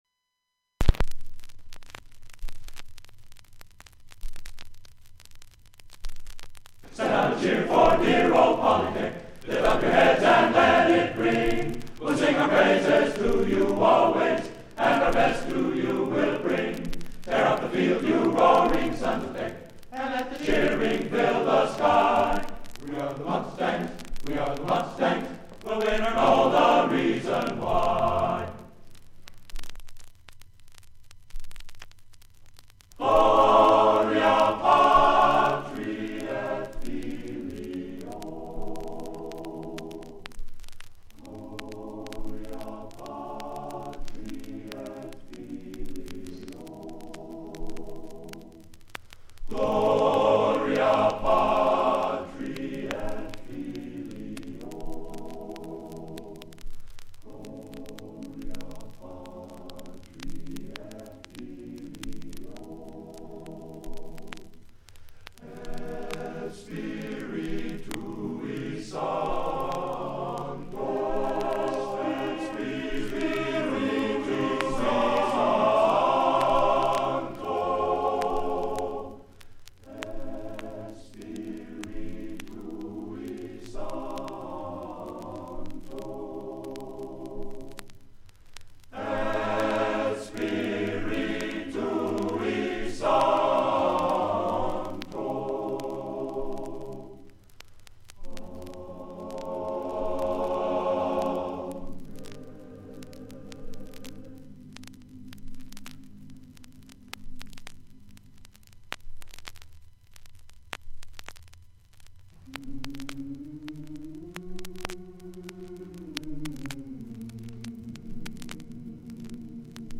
Includes a recording of the concert, and the album art from front and back covers.